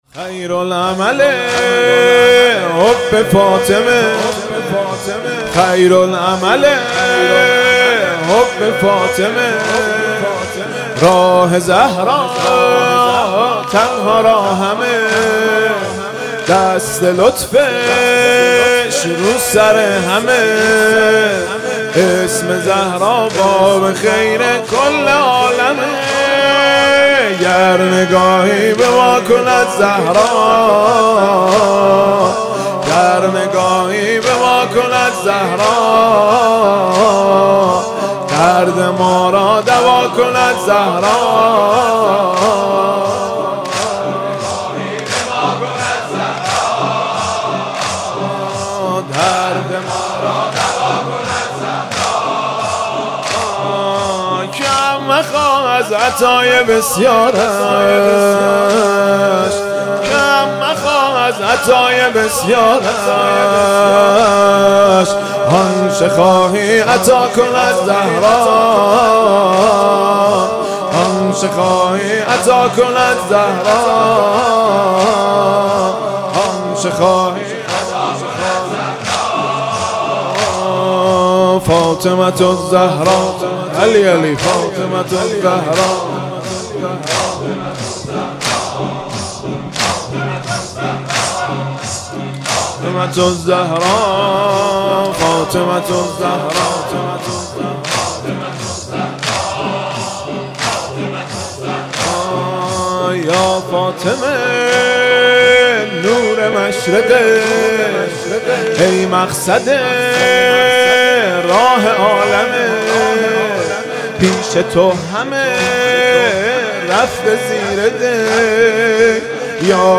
دانلود آهنگ های مداحی و نوحه